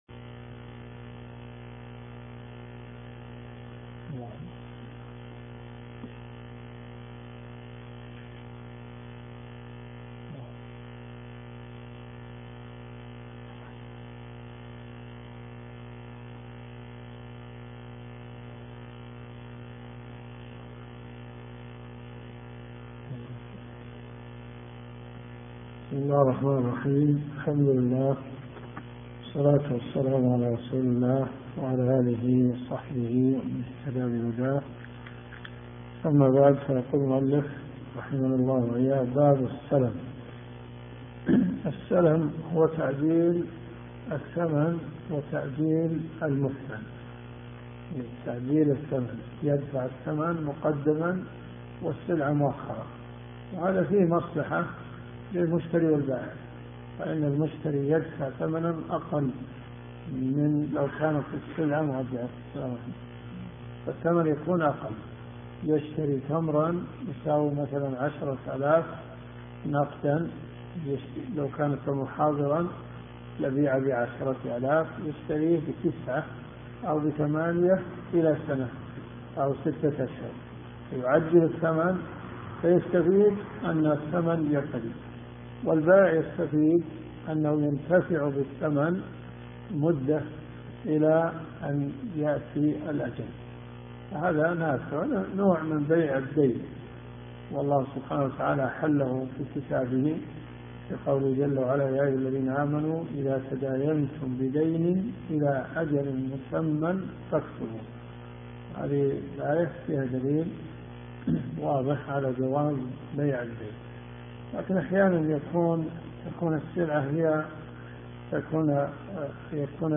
دروس صوتيه
صحيح مسلم . كتاب المساقاة والمزارعة . حديث 4118 -إلى- حديث 4121 . اذا الصوت ضعيف استخدم سماعة الاذن